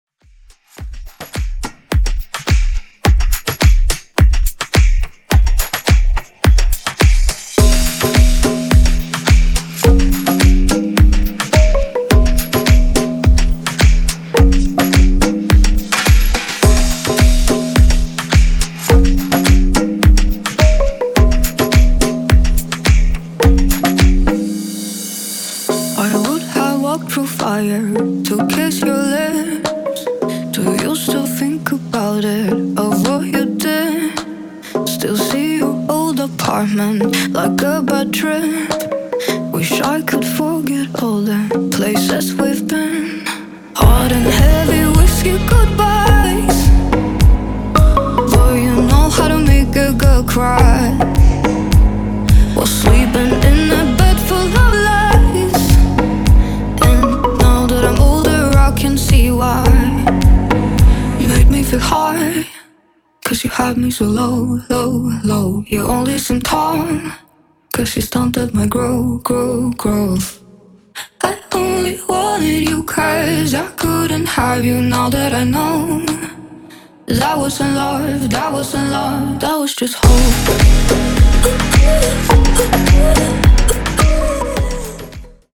Genres: 2000's , HIPHOP , RE-DRUM
Clean BPM: 75 Time